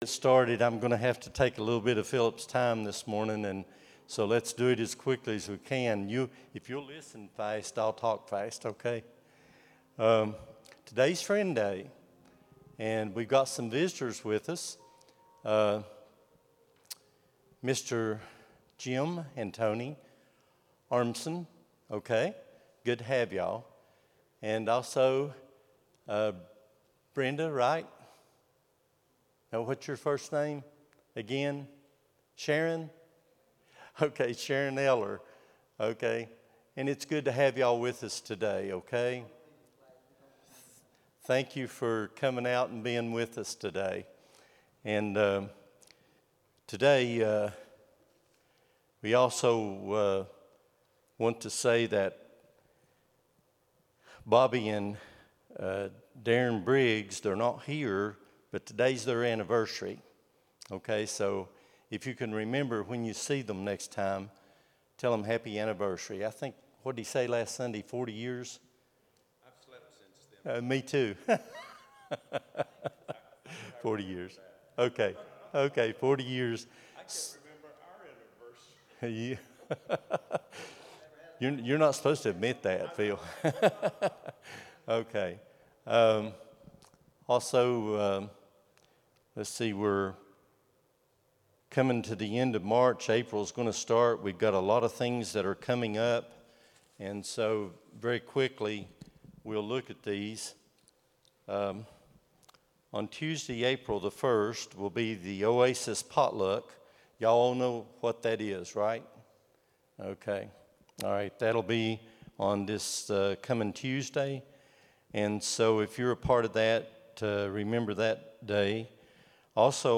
03-30-25 Sunday School | Buffalo Ridge Baptist Church